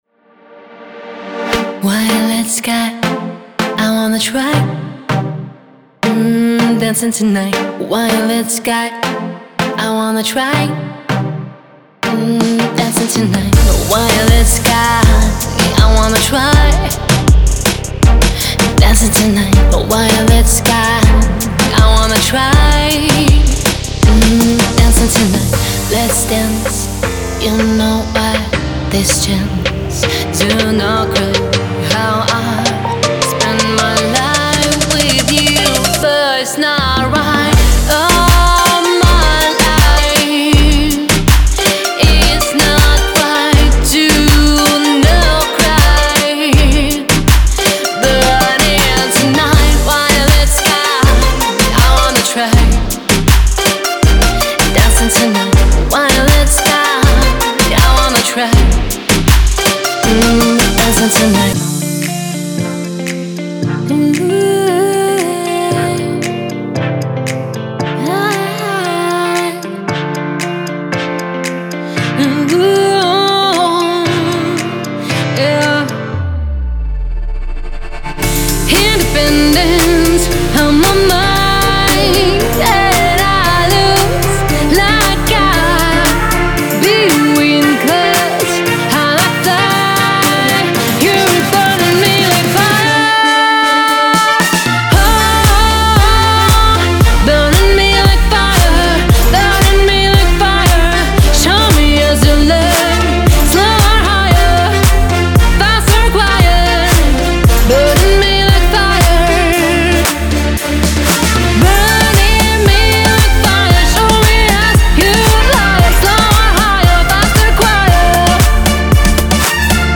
在这个庞大的包中，您会发现快速的踢，拍手和军鼓，带有所有相关MIDI文件的鼓舞人心的音乐循
环，有力的鼓循环，有机SFX和血清预设来自定义您自己的声音。
歌手的独特无伴奏合唱曲目!您可以在演示轨道中听到所有这些构建套件的预览。